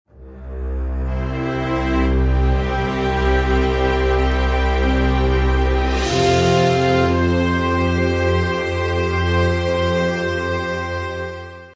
Download cinematic sound effect for free.
Cinematic